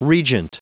Prononciation du mot regent en anglais (fichier audio)
Prononciation du mot : regent